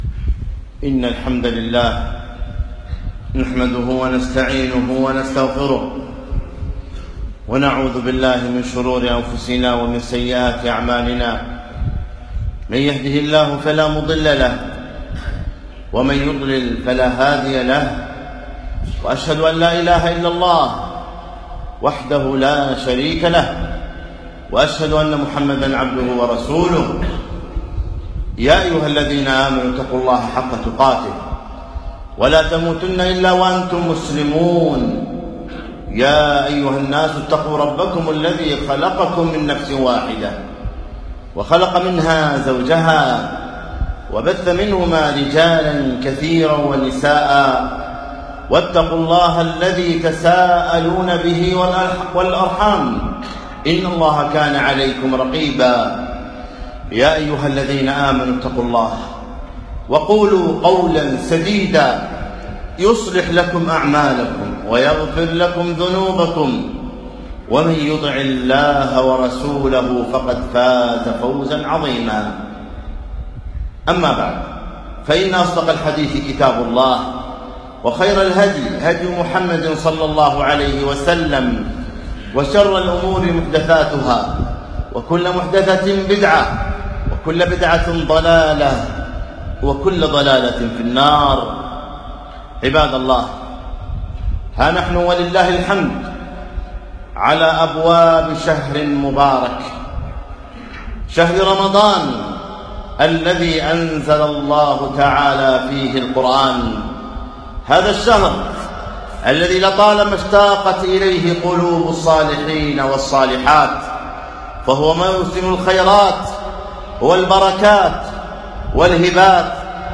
الجمعة 27 شعبان 1437 الموافق 3 6 2016 مسجد عطارد بن حاجب الفروانية